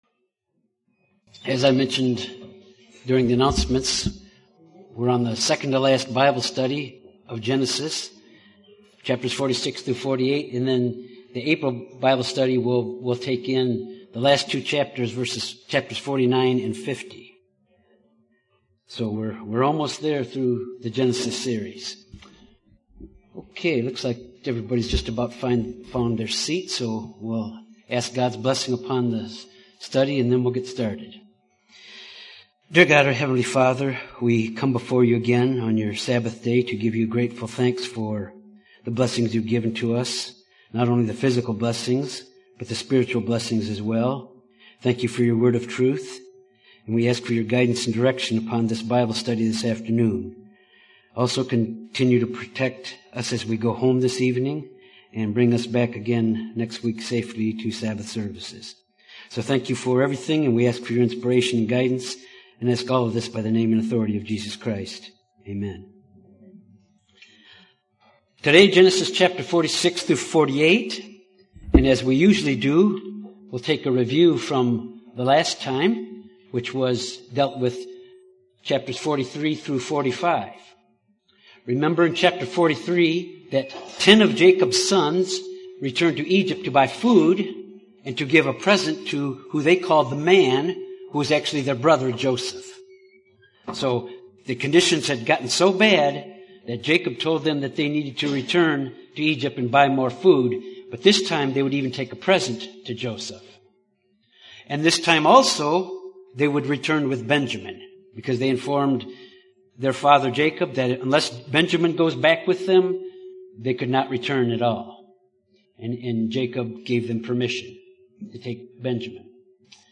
This Bible Study deals with Jacob's journey to Egypt and being united with Joseph.